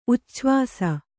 ♪ ucchvāsa